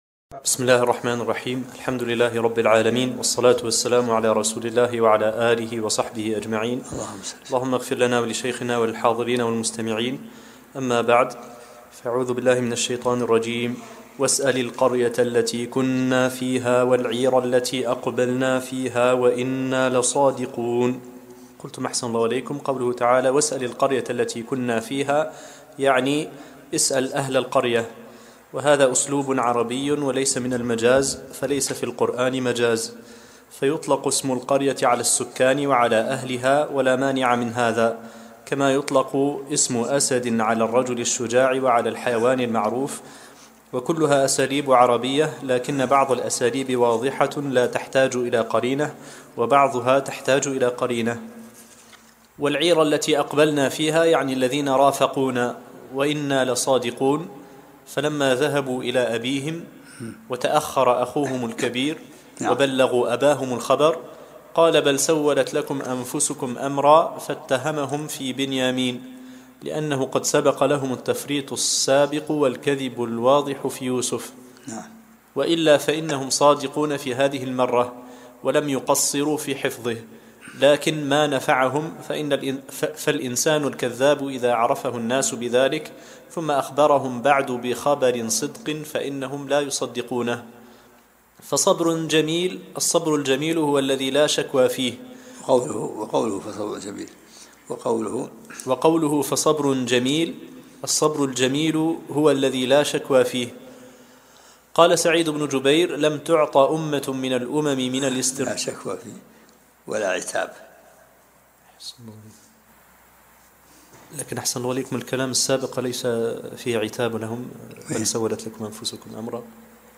الدرس التاسع عشرمن سورة يوسف